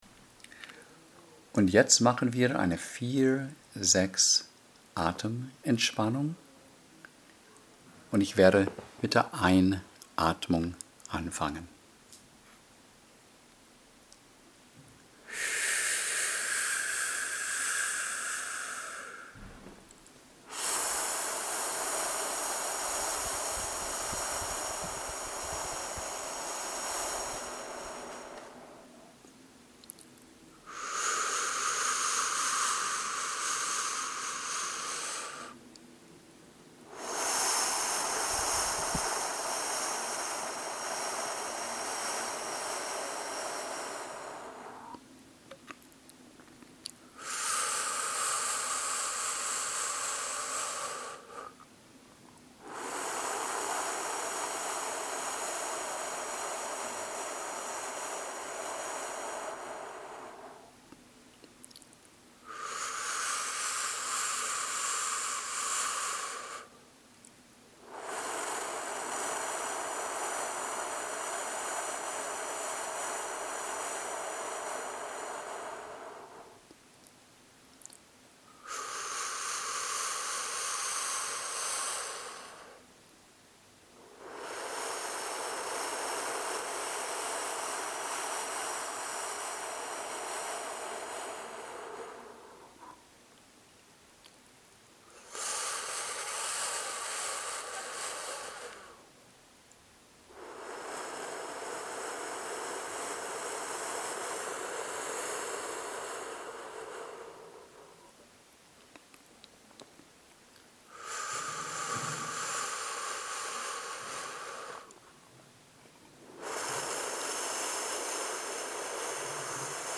4-6-Breathing Voice Recording
4-6_atmung.mp3